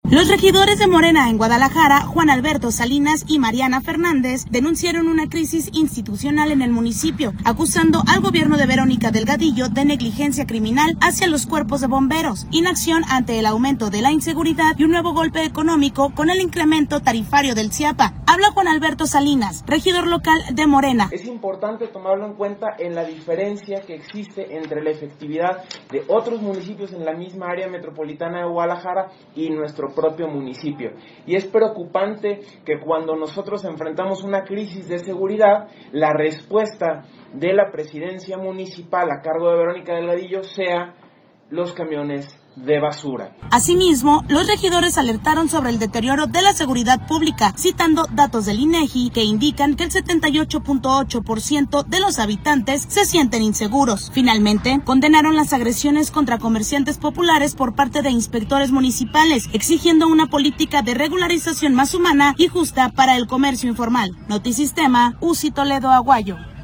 Habla Juan Alberto Salinas, regidor local de Morena.